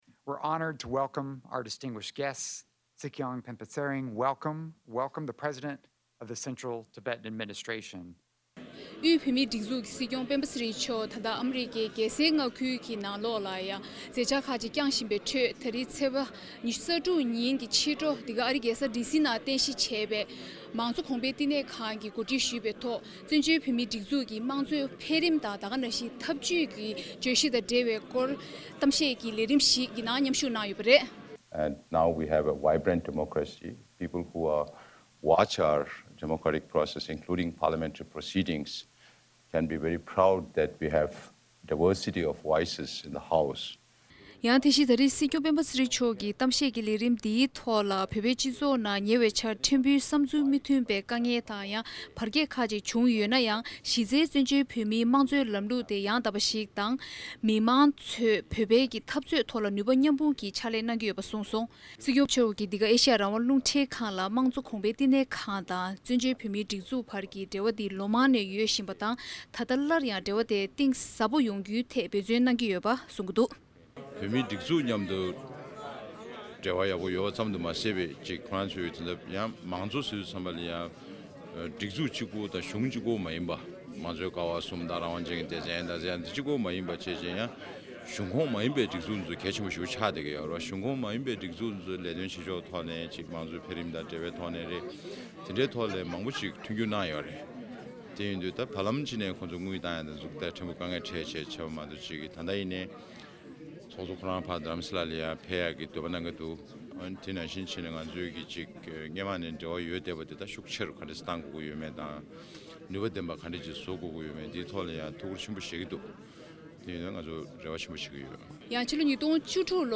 སྲིད་སྐྱོང་སྤེན་པ་ཚེ་རིང་ལགས་ཀྱིས་ཨ་རིའི་རྒྱལ་ཡོངས་མང་གཙོ་གོང་སྤེལ་བསྟི་གནང་ཁང་དུ་བོད་ཀྱི་མང་གཙོའི་འཕེལ་རིམ་དང་ཐབས་ཇུས་སྐོར་གཏམ་བཤད་གནང་འདུག